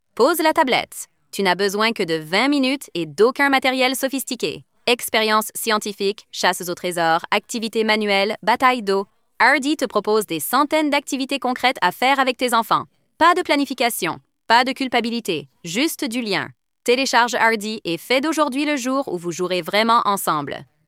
voiceover.mp3